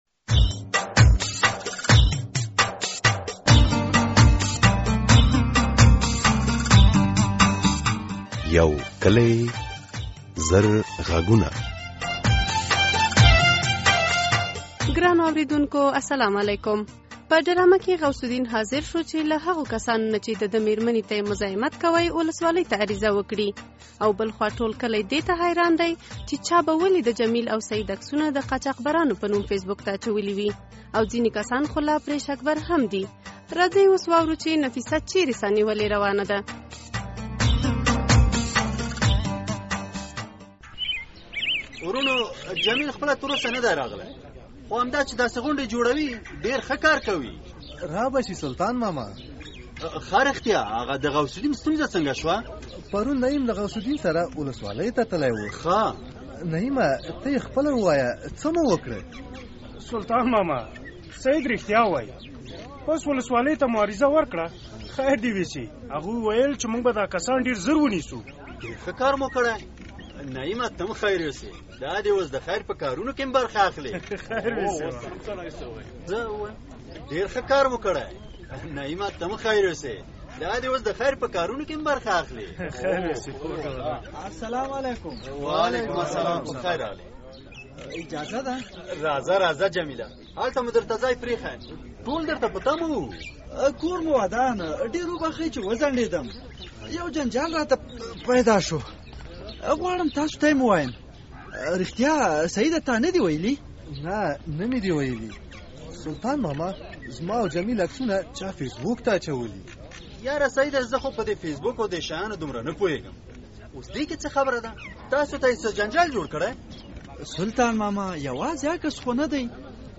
یو کلی زر غږونه ډرامه